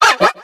Audio / SE / Cries / DUCKLETT.ogg
DUCKLETT.ogg